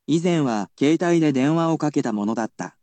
I will also tell you the pronunciation of the word using the latest in technological advancements.